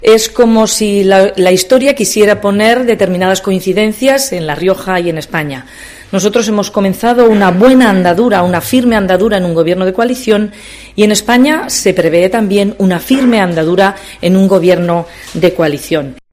La presidenta del Gobierno de La Rioja, Concha Andreu, ha comparecido esta mañana tras el Consejo de Gobierno celebrado en Nájera para analizar la formación del nuevo gobierno nacional y el impacto "positivo que supone para La Rioja".